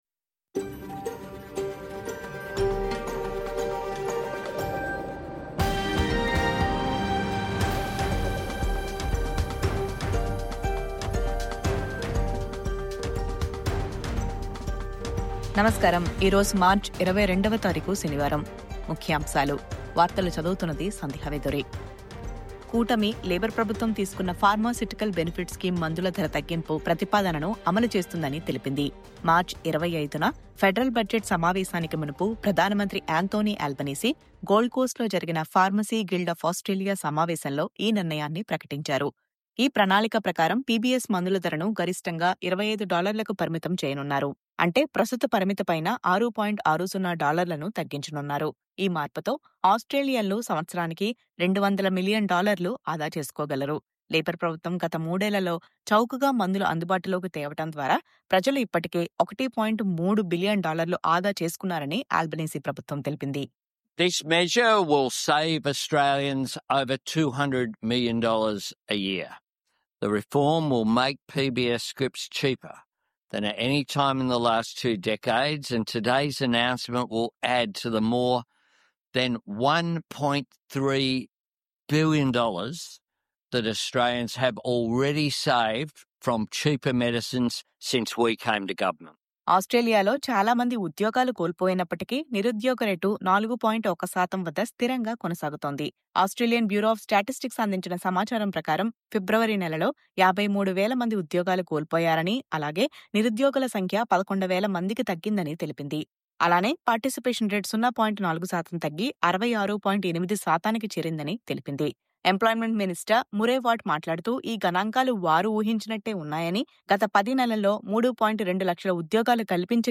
ఈ రోజు మార్చి 22వ తారీఖు , శనివారం – SBS తెలుగు వార్తలు.